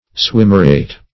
Swimmeret \Swim"mer*et\, n. (Zool.)